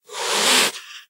25w18a / assets / minecraft / sounds / mob / cat / hiss3.ogg
hiss3.ogg